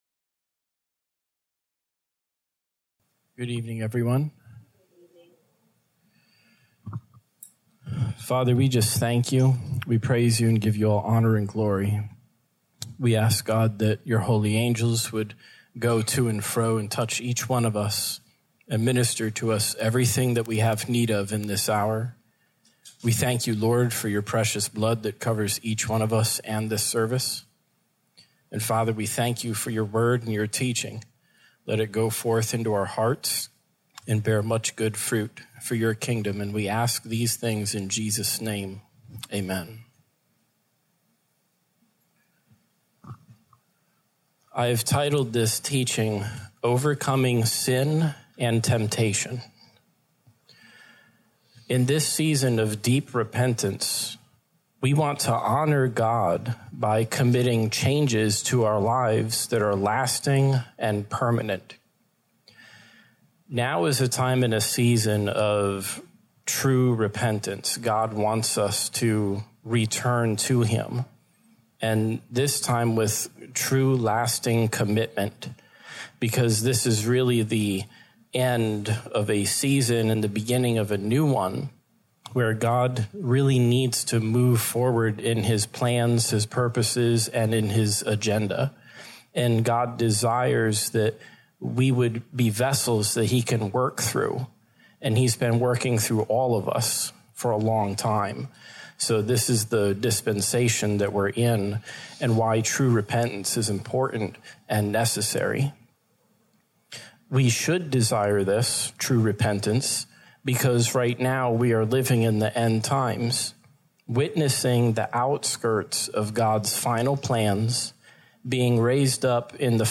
Posted in Sermons Tagged with Mindsets